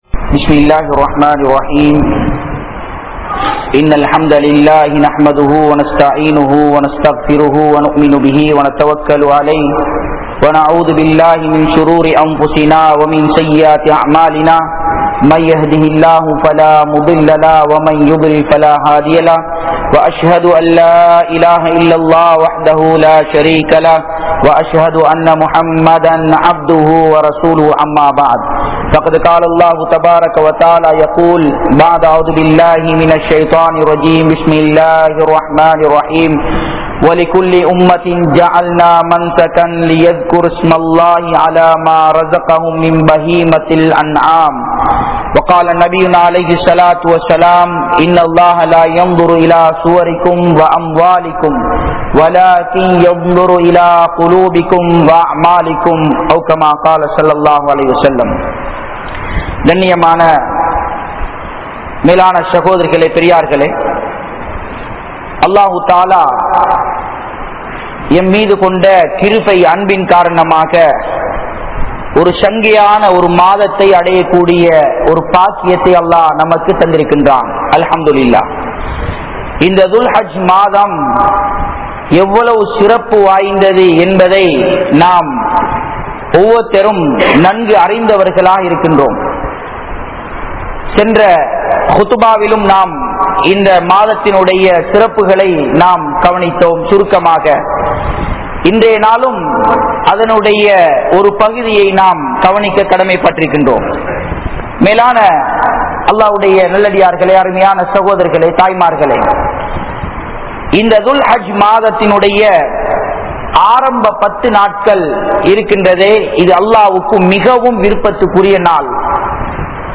Ulhiya (உழ்ஹிய்யா) | Audio Bayans | All Ceylon Muslim Youth Community | Addalaichenai